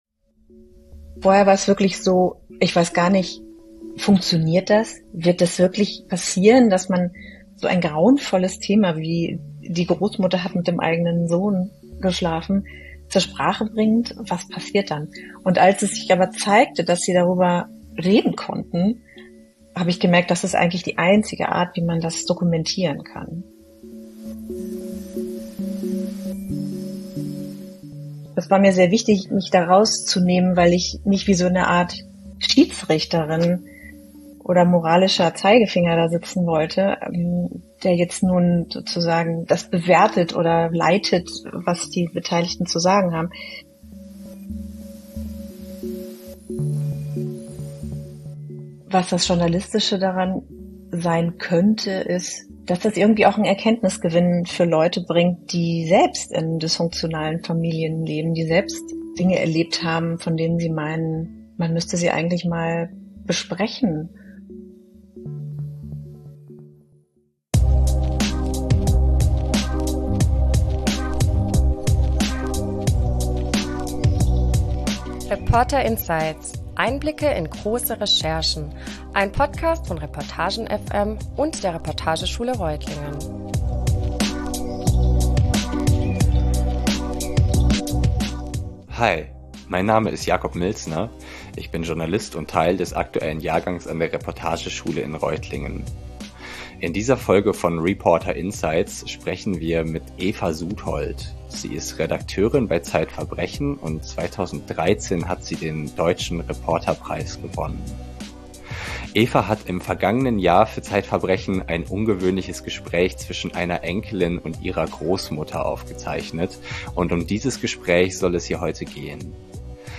Bei reporter:insights sprechen wir über große Recherchen und die Fragen, die sie für uns junge Journalist:innen aufwerfen. Wir analysieren mit den Autor:innen Handwerk, Hürden und Zweifel.